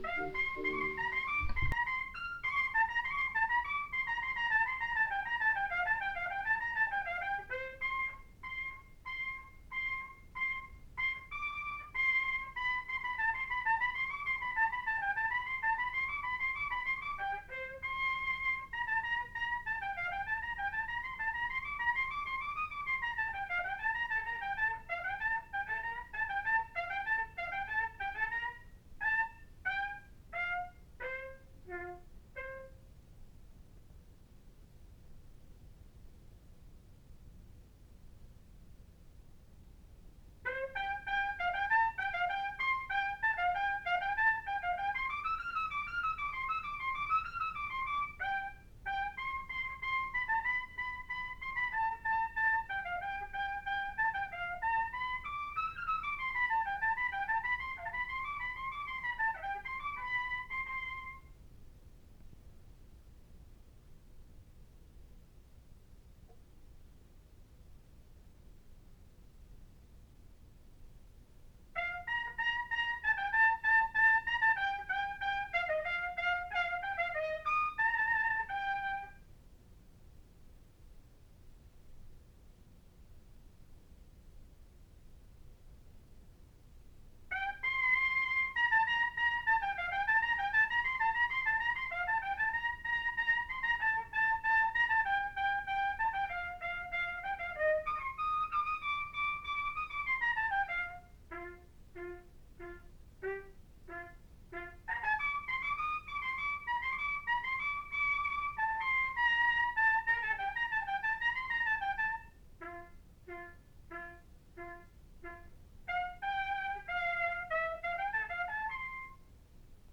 I wanted to put some recent of my playing on a tape to submit with my resume for applying for teaching jobs so I recorded me playing
Bach's Brandenburg Concerto 2 Allegro on piccolo trumpet and